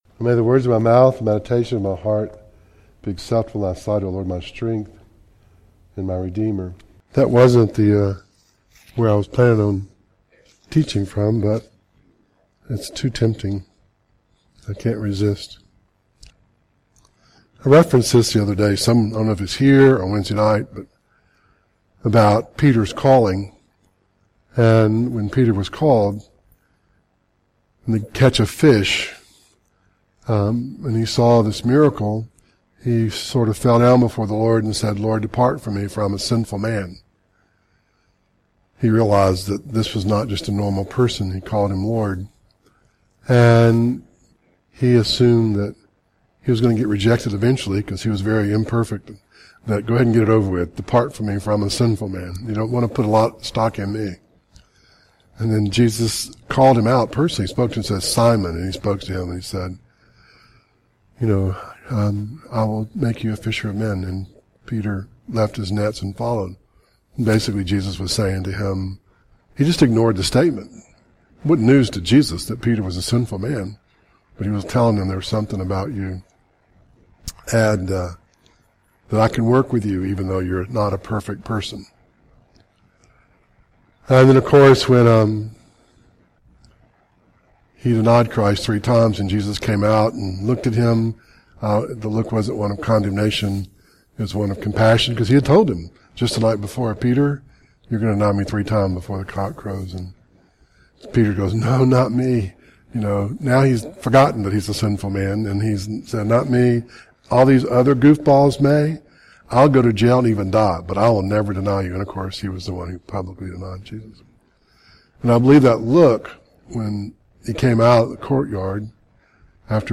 Ephesians 3 Service Type: Devotional